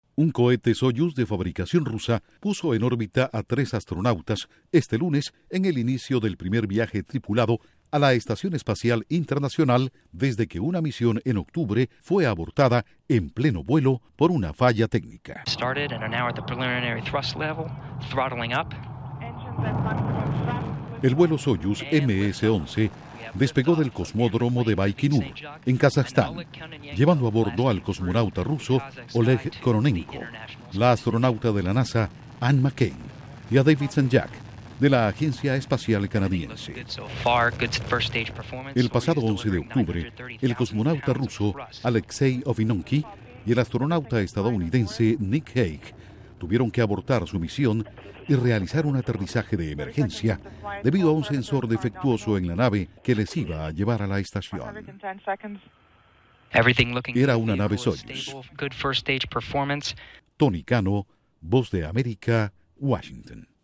Astronautas de Estados Unidos y Canadá, y un cosmonauta de Rusia en el primer viaje tripulado a la Estación Especial Internacional desde el accidente de un Soyuz. Informa desde la Voz de América en Washington